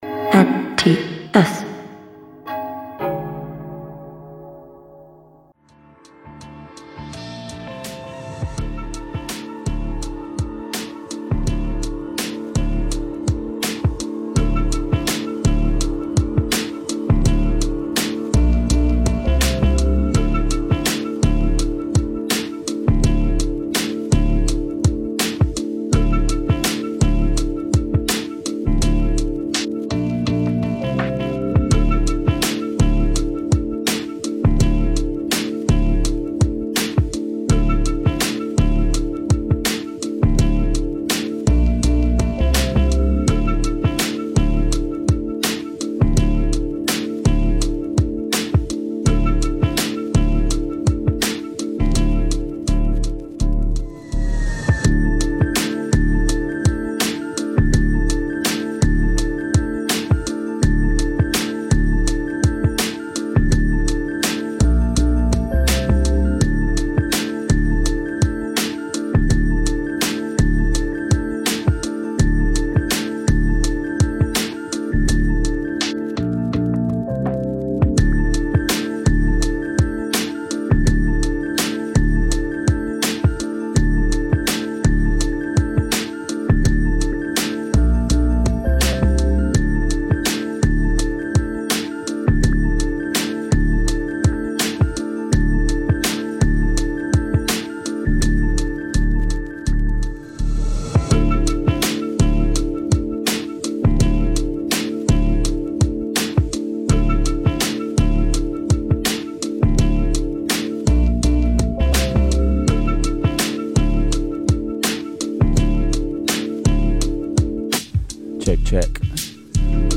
Joined live in the studio
a guest mix